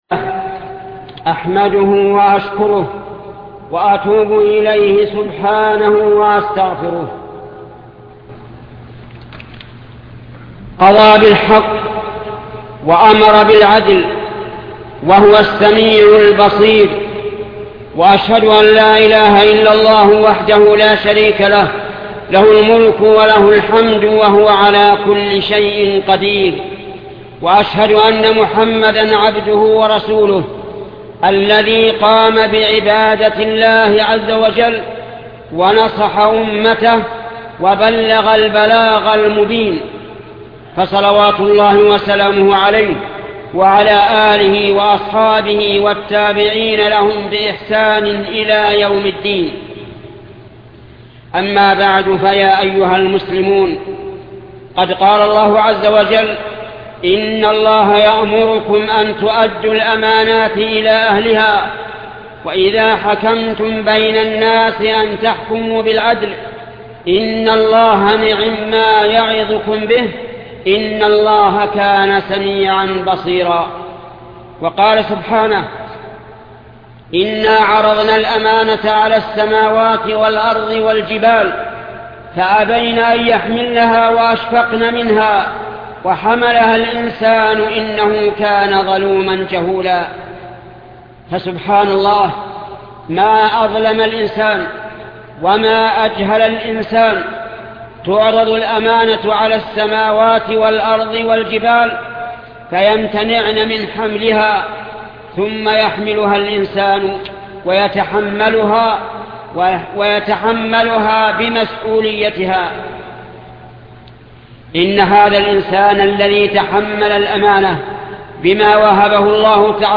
خطبة أداء الأمانة في اختبارات المدارس ـ أداء الأمانة في العدل بين الزوجات والأولاد الشيخ محمد بن صالح العثيمين